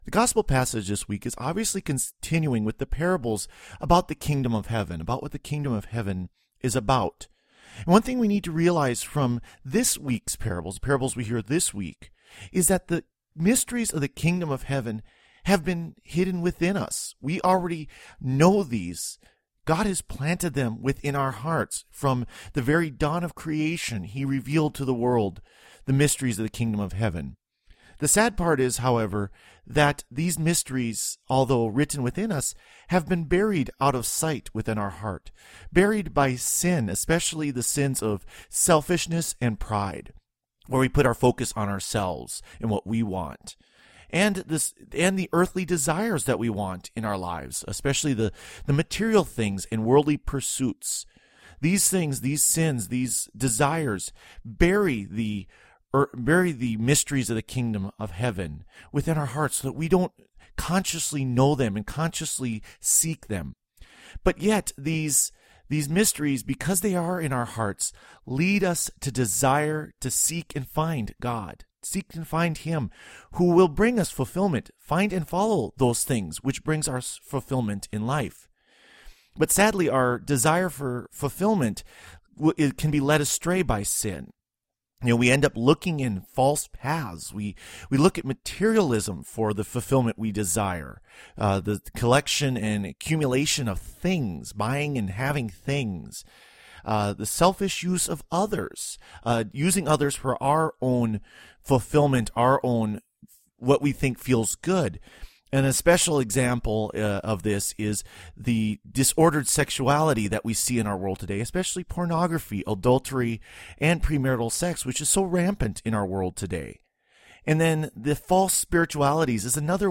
Homily for the Seventeenth Sunday in Ordinary Time